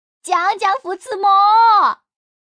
Index of /xiaoxiang/update/3018/res/sfx/changsha_woman/